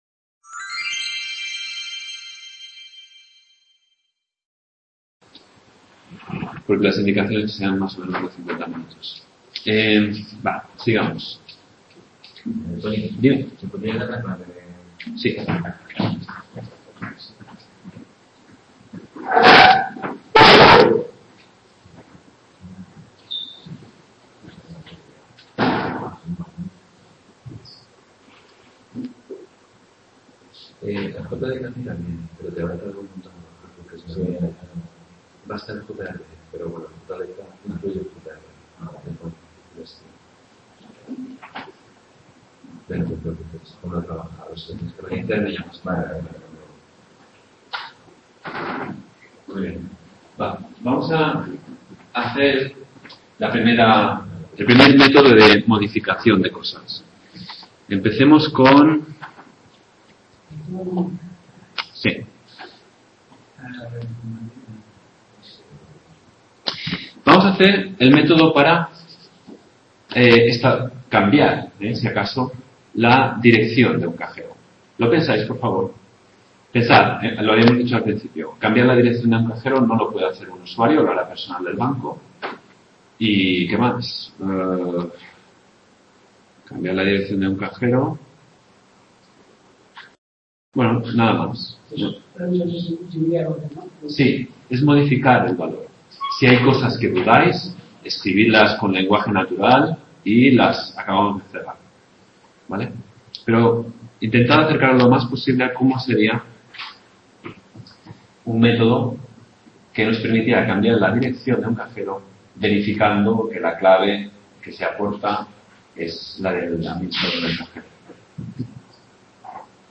Tutoría 2ª, ejemplo de implementación de la clase Cajero automático